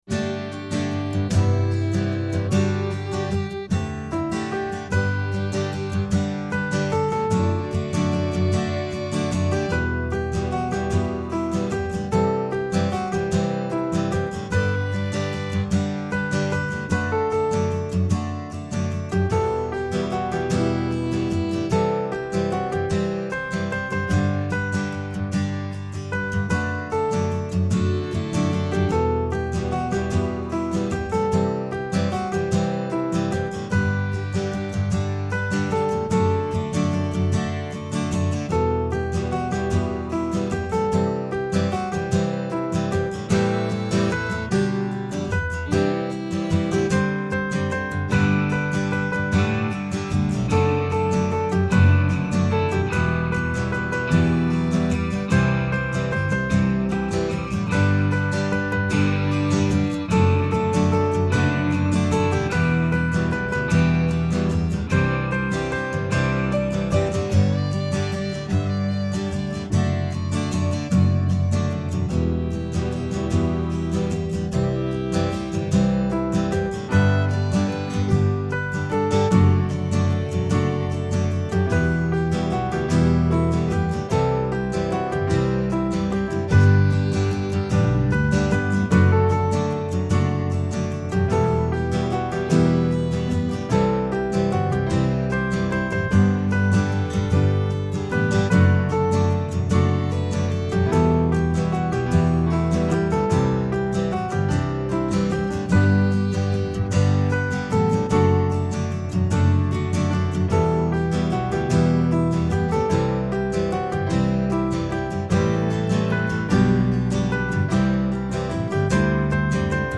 devotional solo piece